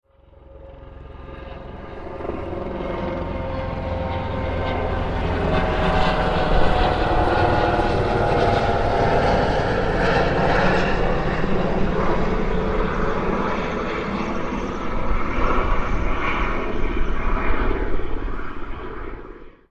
Descarga de Sonidos mp3 Gratis: helicoptero 7.
helicopter-pass.mp3